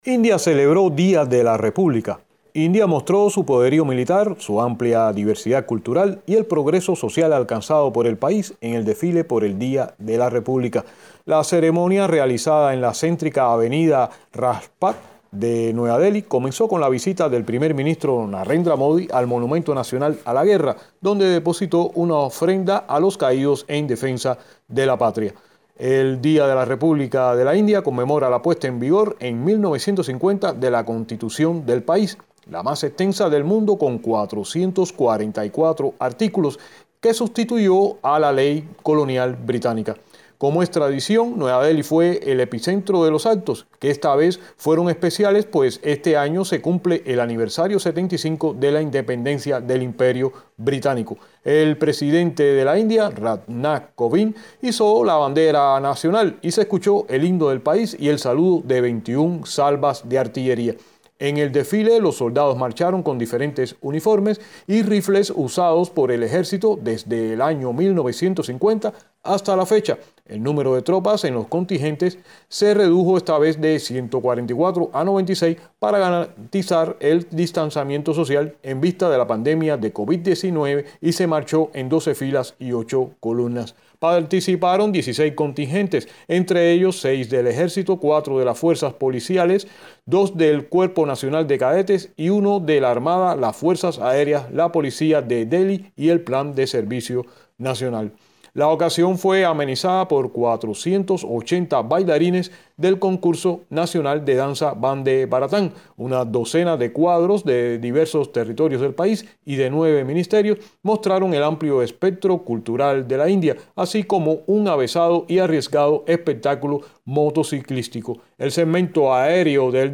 desde Nueva Dheli.